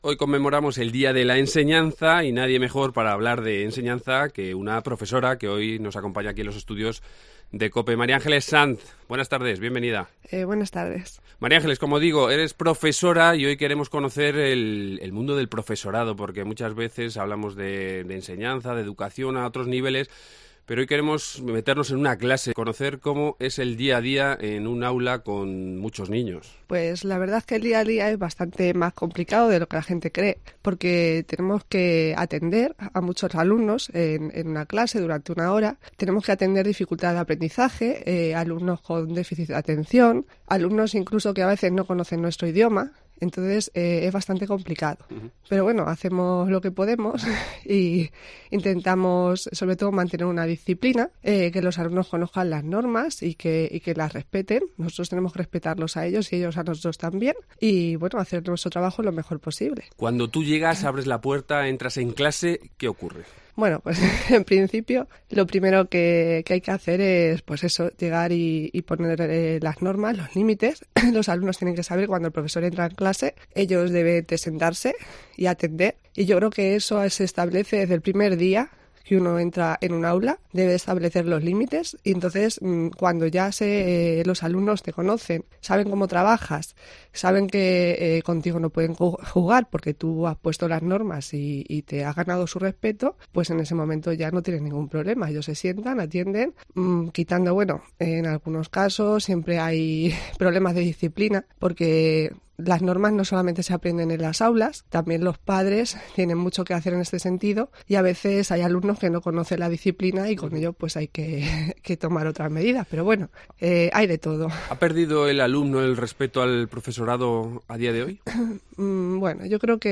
Hoy es el Día de la Enseñanza y en Cope queremos poner en valor la labor que realizan nuestros profesores y maestros, queremos incidir en la importancia capital que tiene la educación. Hablamos con una docente